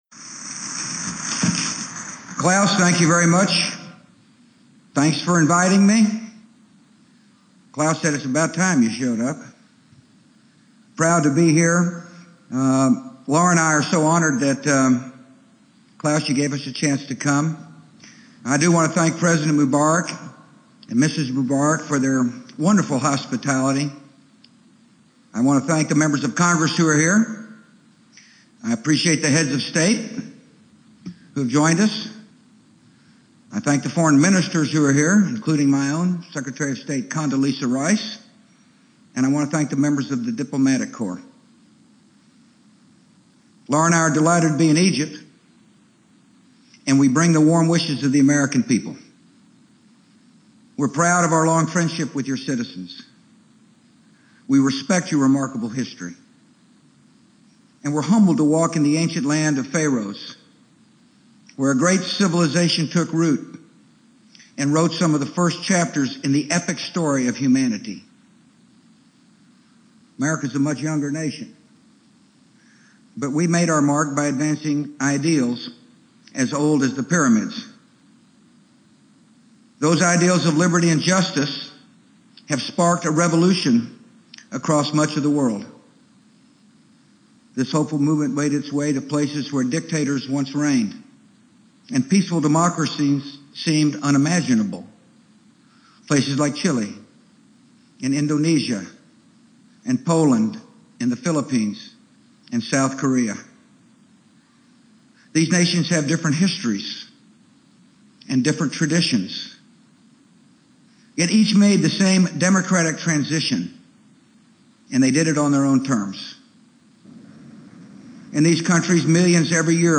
American Rhetoric: George W. Bush - 2008 World Economic Forum Address